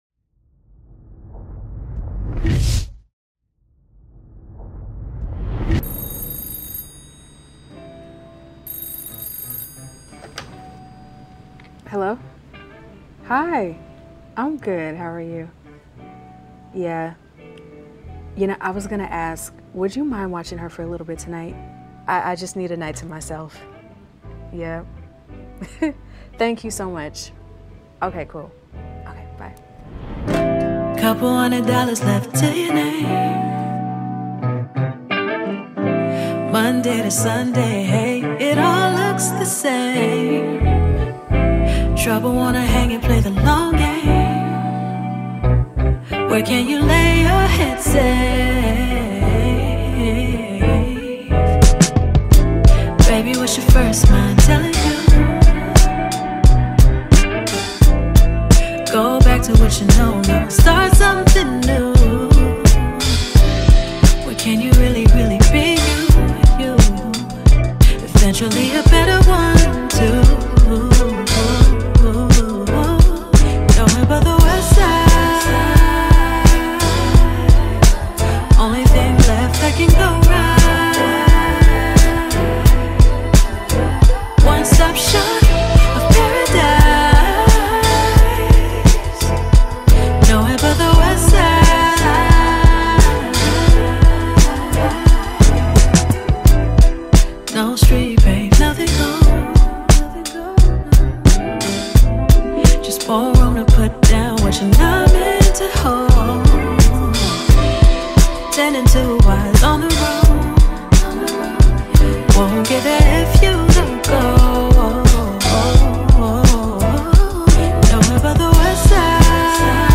heartwarming song